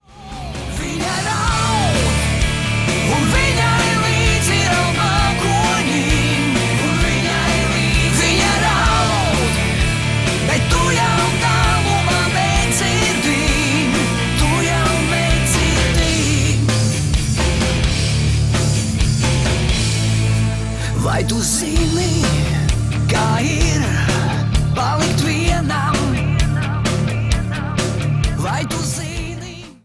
• Качество: 320, Stereo
мужской вокал
дуэт
Pop Rock
легкий рок